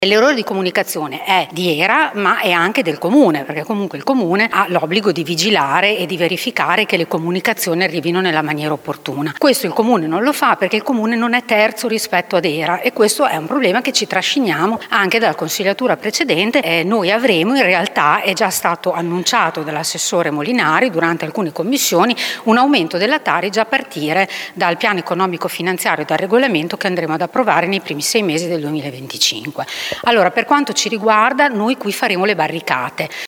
Elisa Rossini,  Fratelli d’Italia…